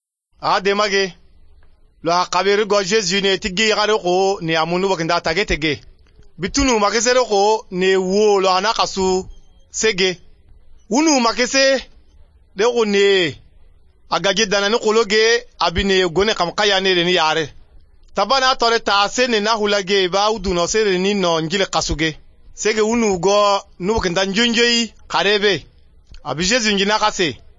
5 June 2016 at 12:04 pm Hmmm, sub-Saharan Africa… with L and H tones (as expected for the region) but with uvular consonants (not expected)…